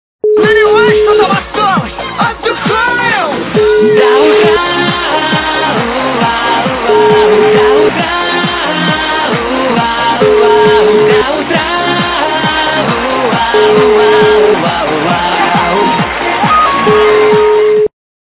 - русская эстрада
- Remix
качество понижено и присутствуют гудки.